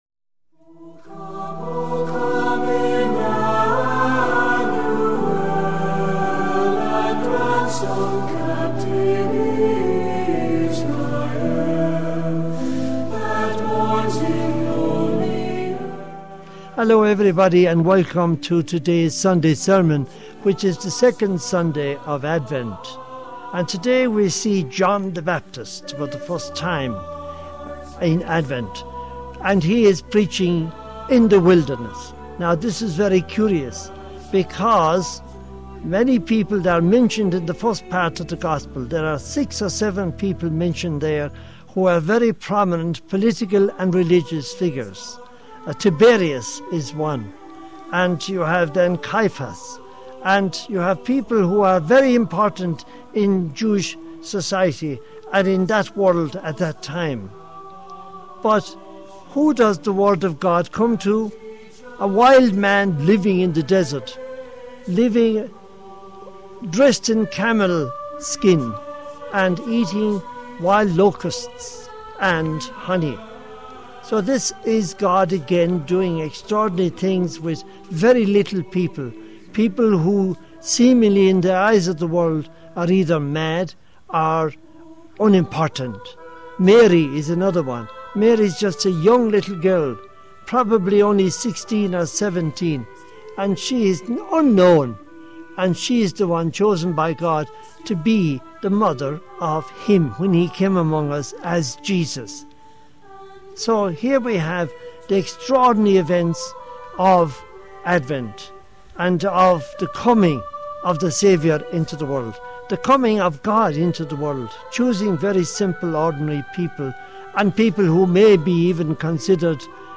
Homily for the 2nd Sunday of Advent, Year C